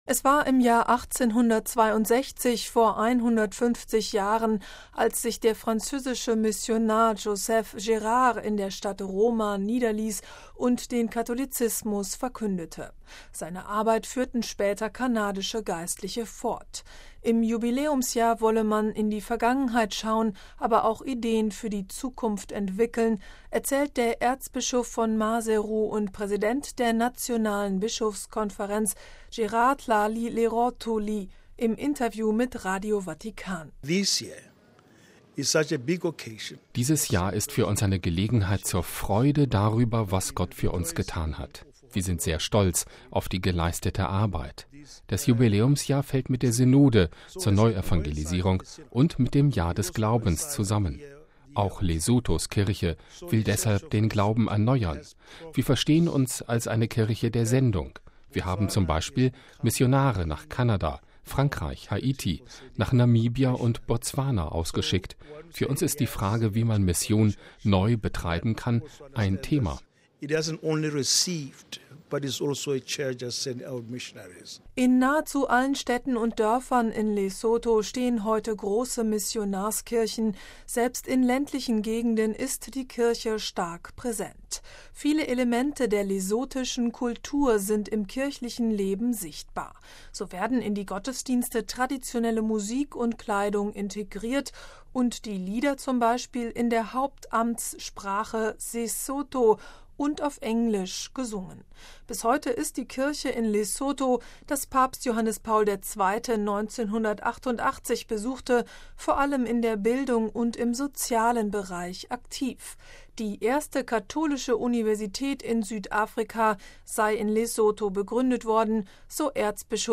Im Jubiläumsjahr wolle man in die Vergangenheit schauen, aber auch Ideen für die Zukunft entwickeln, erzählt der Erzbischof von Maseru und Präsident der nationalen Bischofskonferenz, Gerard Tlali Lerotholi (OMI), im Interview mit Radio Vatikan: